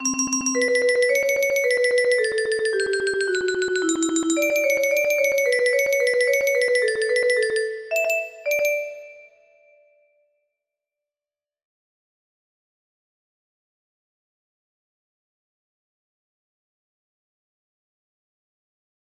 medody music box melody